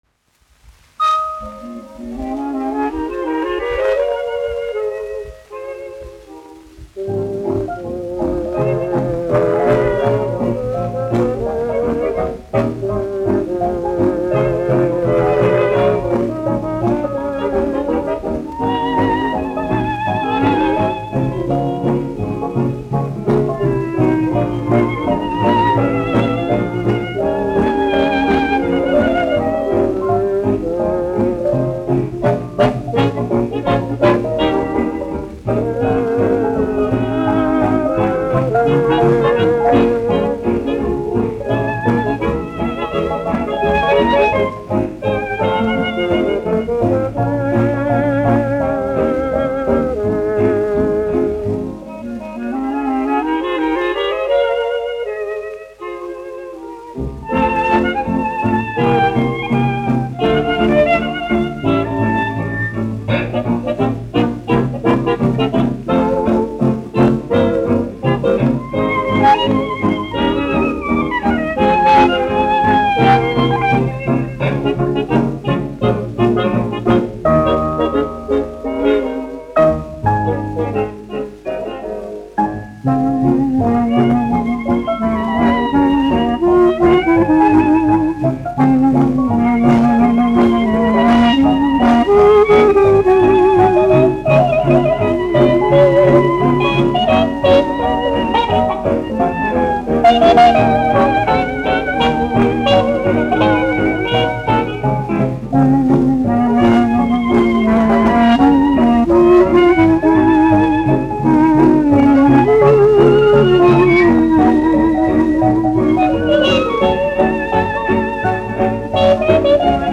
1 skpl. : analogs, 78 apgr/min, mono ; 25 cm
Fokstroti
Populārā instrumentālā mūzika
Latvijas vēsturiskie šellaka skaņuplašu ieraksti (Kolekcija)